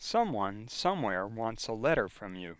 Sounds for Exercise I Chapter 4 spoken by an American Speaker